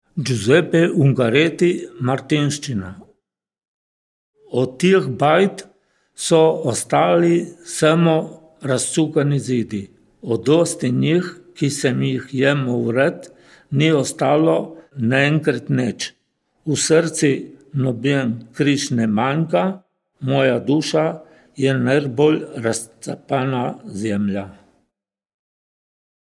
DIALETTI DELLA VALLE DEL VIPACCO
Registrazione audio del dialetto della valle del Vipacco,  Casa di riposo di Gradišče: